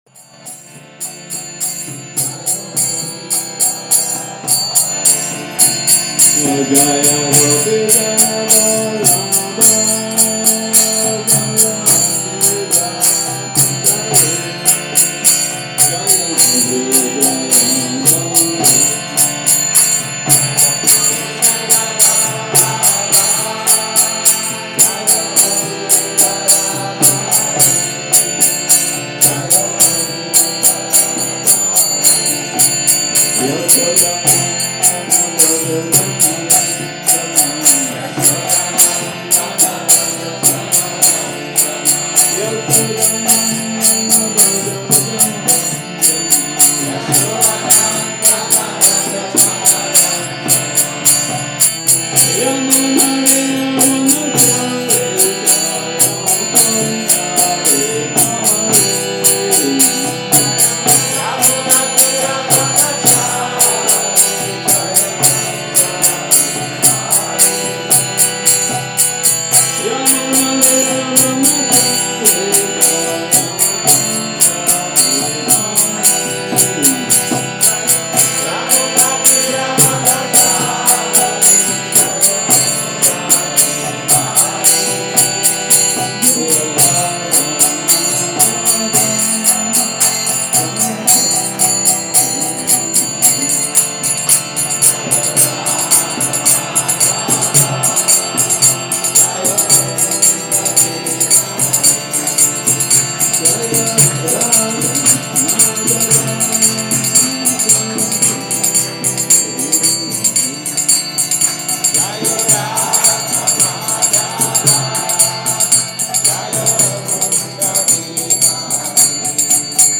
Přednáška BG-9.25 – restaurace Góvinda